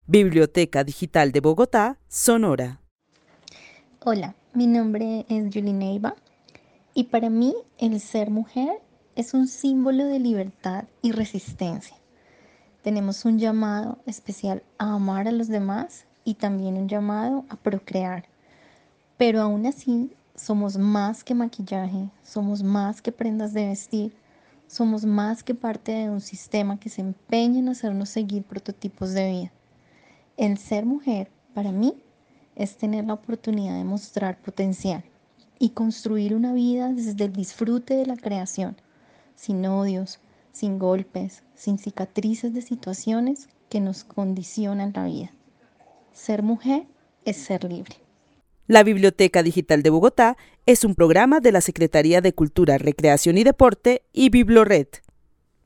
Narración oral sobre lo que significa ser mujer.